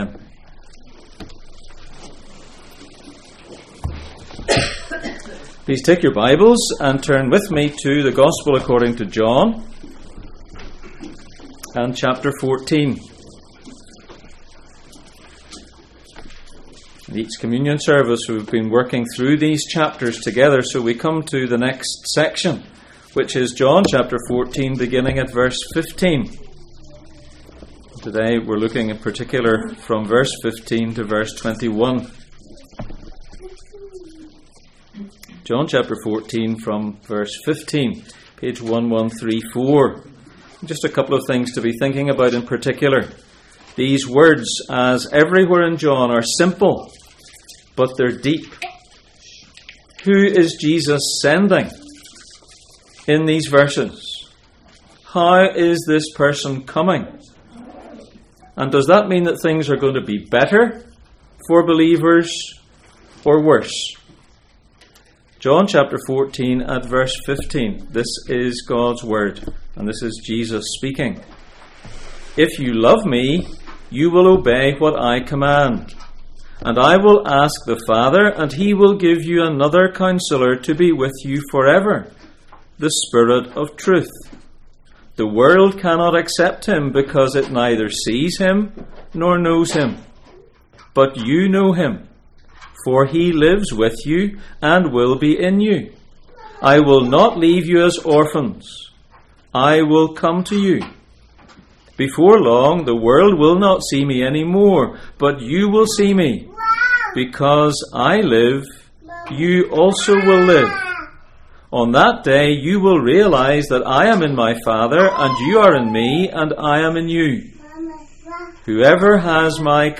The Lord's Table Passage: John 14:15-21, Acts 5:3-4, 1 Corinthians 11:23-32 Service Type: Sunday Morning